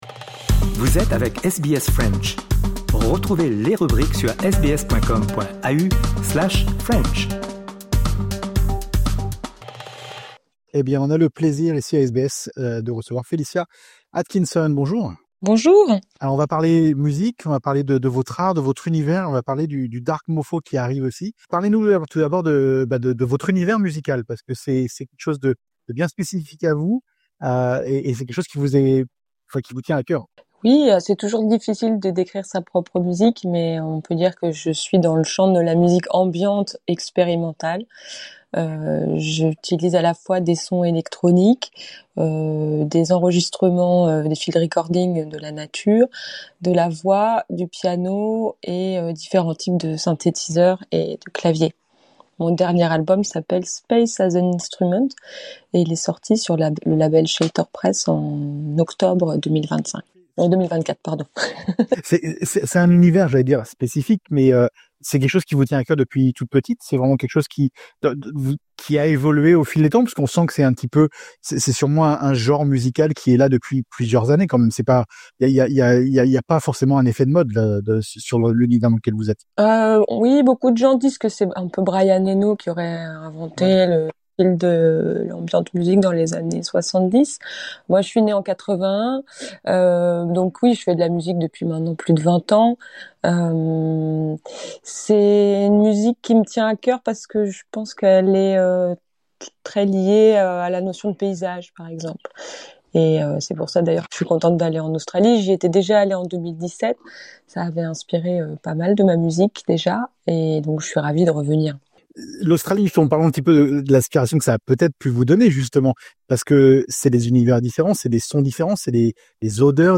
Rencontres